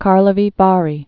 (kärlə-vē värē) also Carls·bad or Karls·bad (kärlzbăd, kärlsbät)